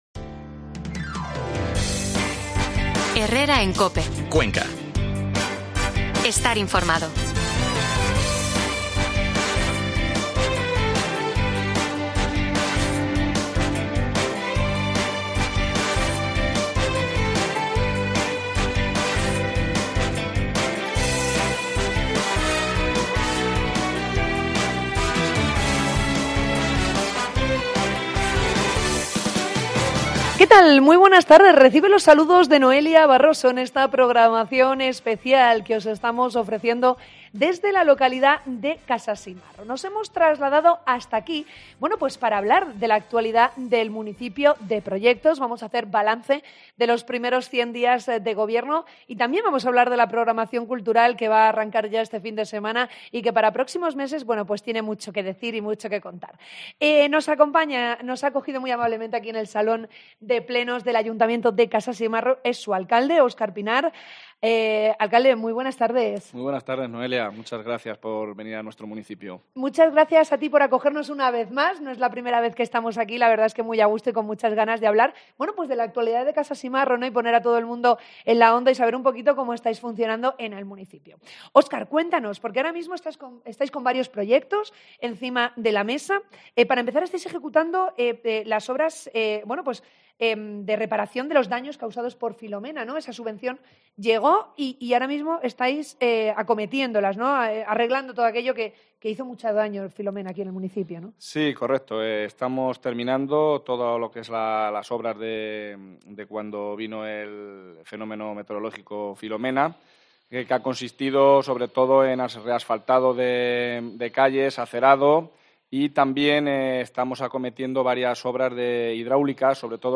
AUDIO: Escucha el programa especial de COPE Cuenca desde la localidad de Casasimarro